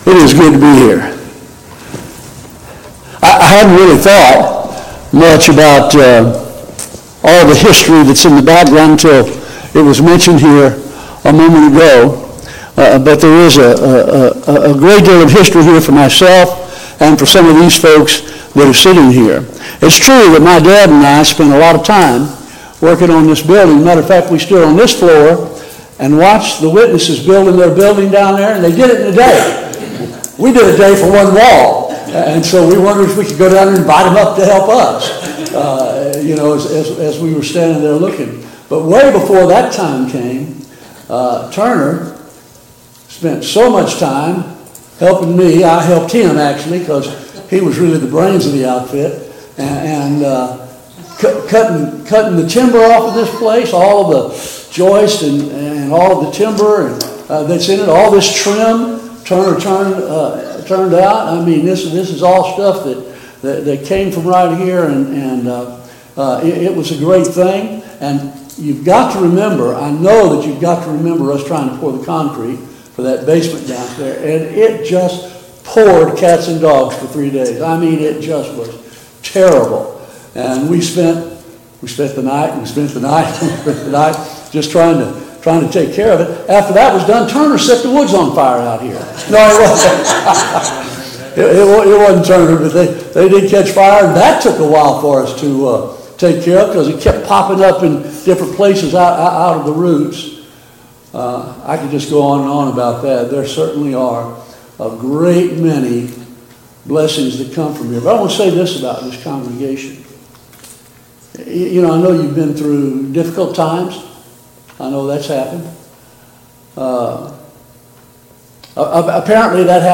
2025 Fall Gospel Meeting Service Type: Gospel Meeting « Study of Paul’s Minor Epistles